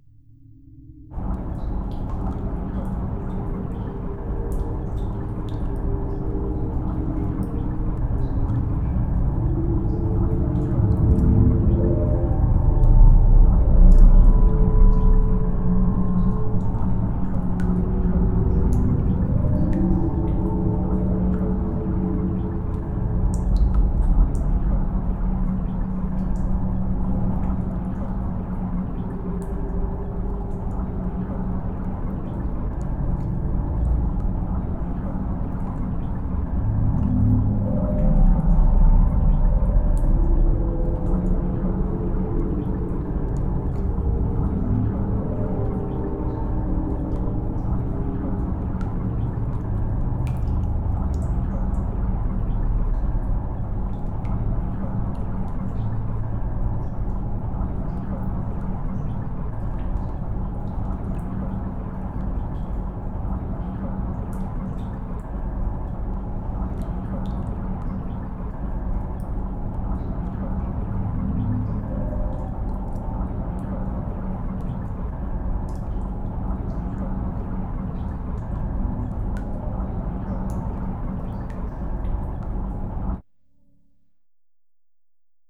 cave_ambience.R.wav